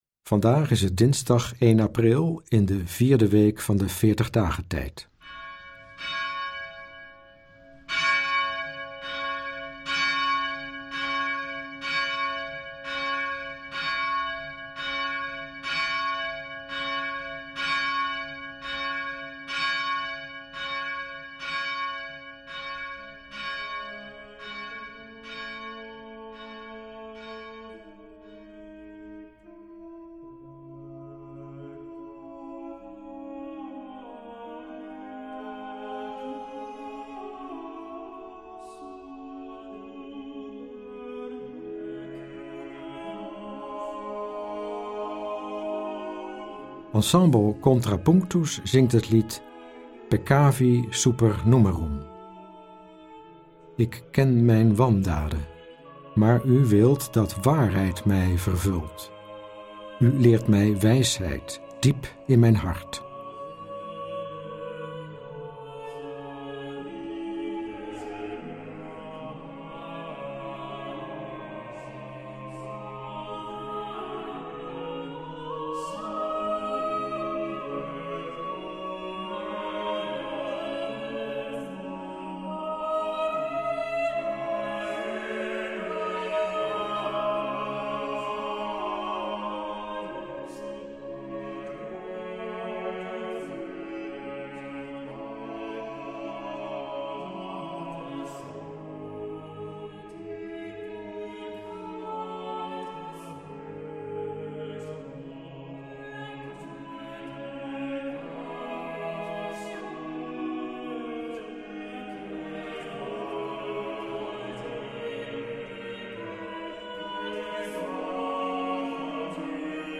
Bidden Onderweg brengt je dichter bij God, met elke dag een nieuwe gebedspodcast. In de meditaties van Bidden Onderweg staan Bijbelteksten central. De muzikale omlijsting, overwegingen y begeleidende vragen helpen je om tot gebed te komen.